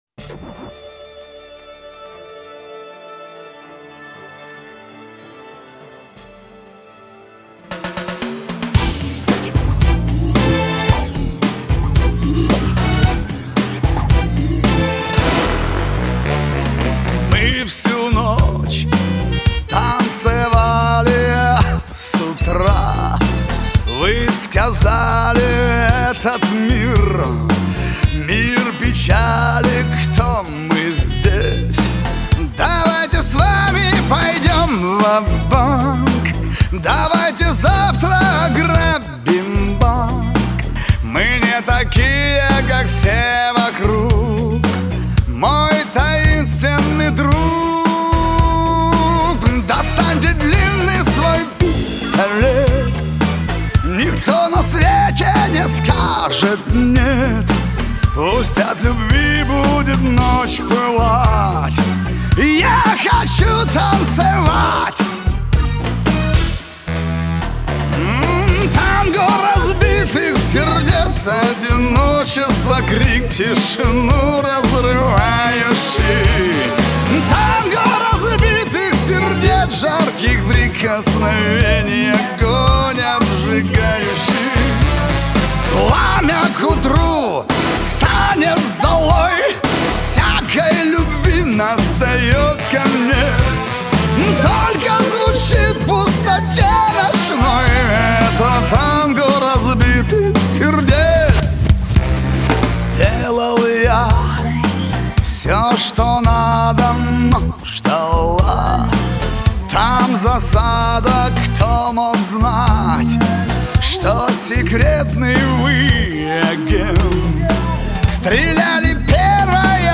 скрипка+хип-хоп (закрыта)